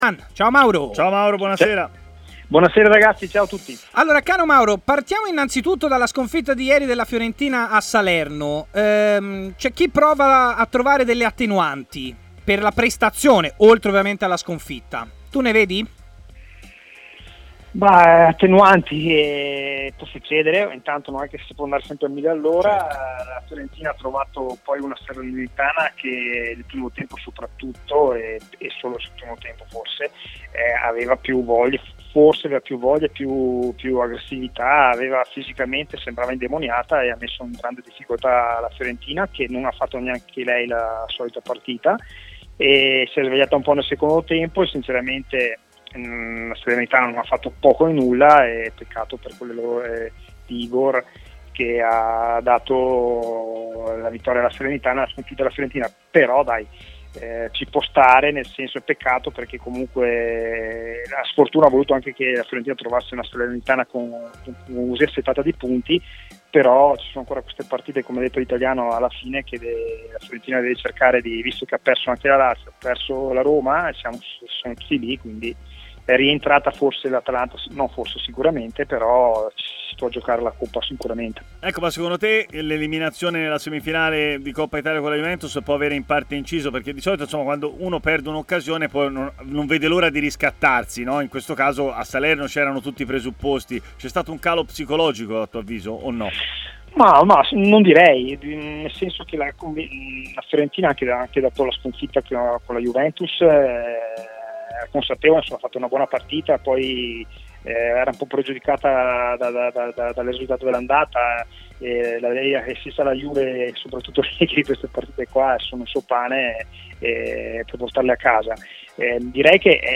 L'ex centrocampista Mauro Bressan ha parlato in diretta a Stadio Aperto, trasmissione di TMW Radio